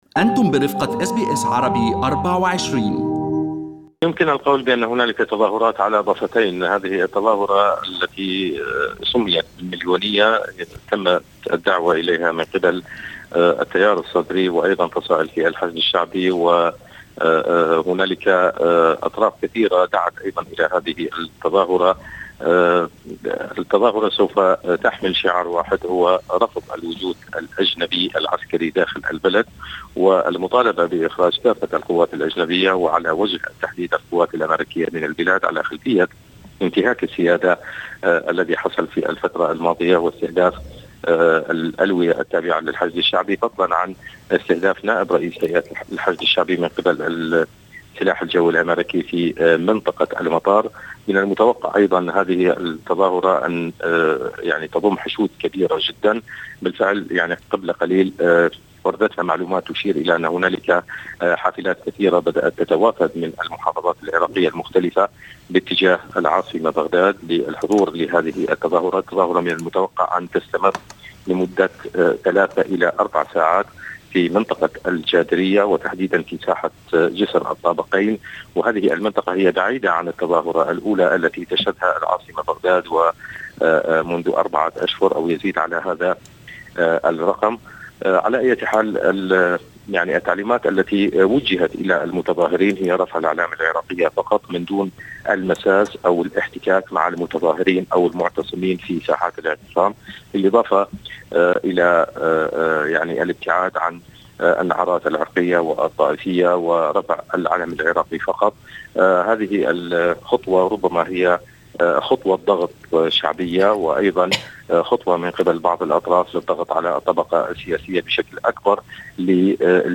وكيف يُنظر الى تصريحات رئيس الجمهورية برهم صالح في مؤتمر دافوس، وماهو الموقف منها داخلياً بعد اشارته الى استمرار الشراكة مع الولايات المتحدة .مراسلنا في بغداد يطلعنا على التفاصيل .